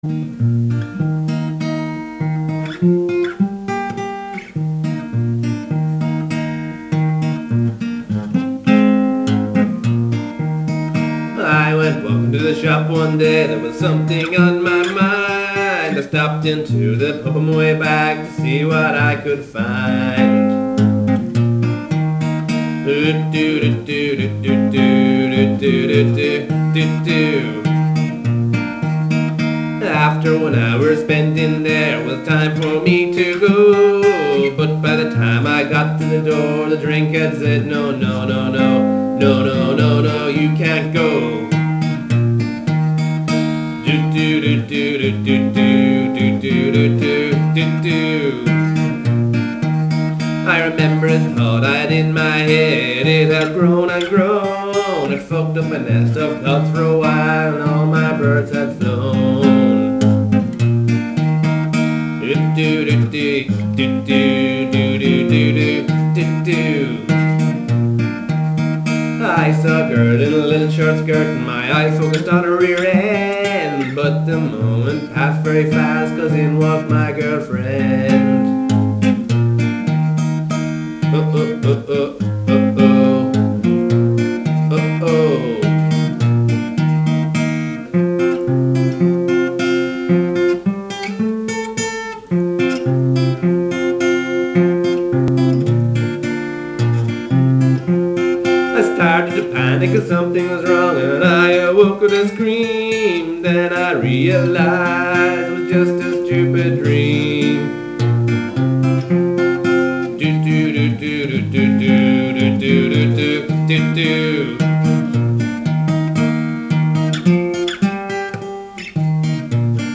quirky nonsense tune
Raw-songs
Folk
Country-rock